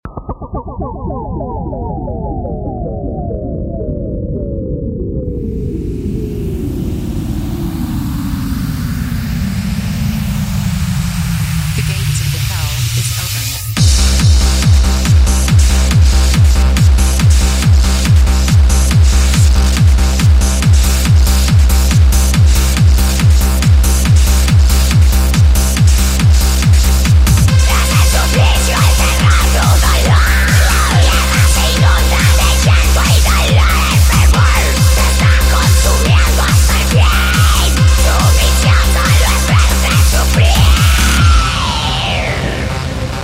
• Качество: 128, Stereo
громкие
жесткие
мощные
быстрые
скриминг
Стиль: electronica